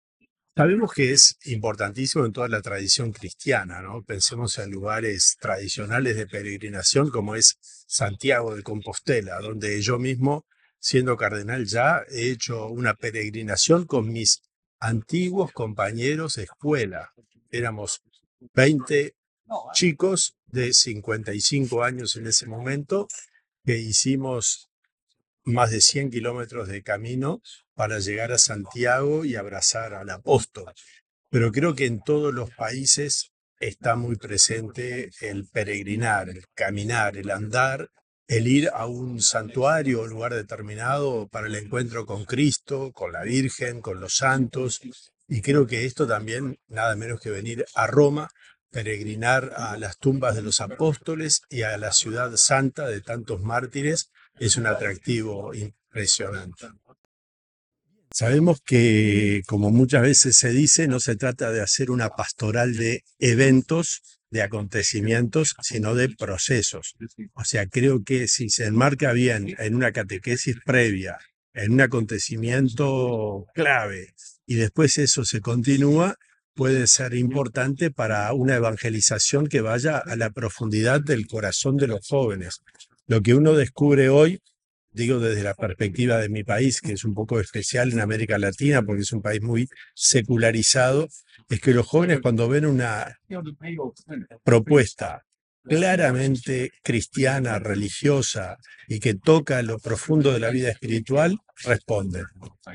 voce-daniel-fernando-sturla-berhouet.mp3